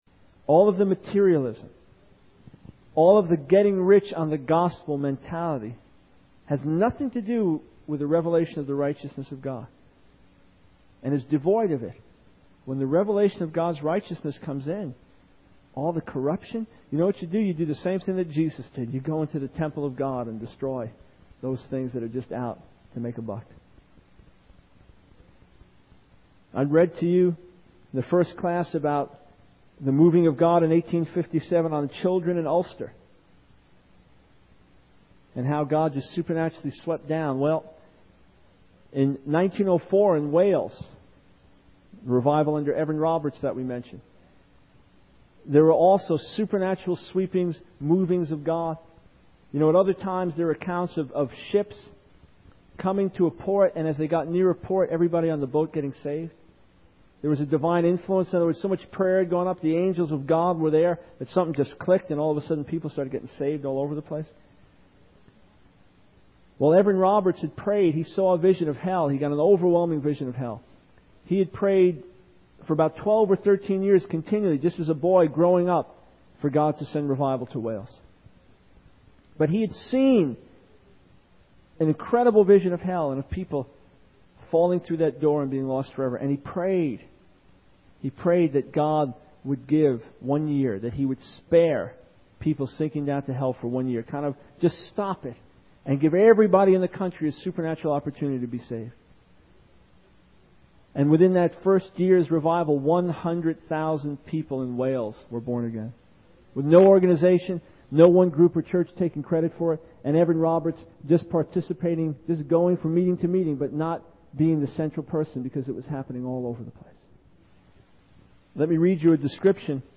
In this sermon, the speaker emphasizes the importance of seeking God's word and understanding His reality.